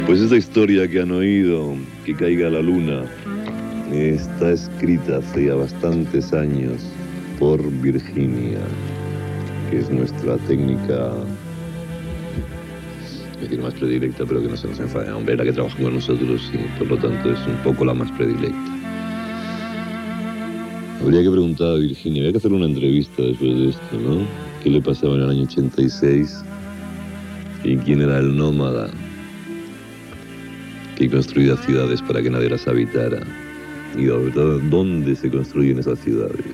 Entreteniment